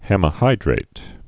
(hĕmĭ-hīdrāt)